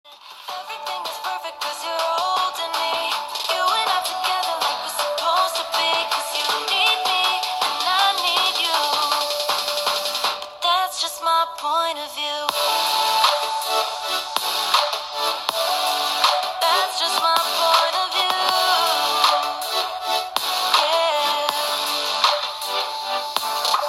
Xperia 10 IIIは、モノラルスピーカーを搭載。肝心の音については、正直物足りない印象です。
▼Xperia 10 IIIのモノラルスピーカーの音はこちら！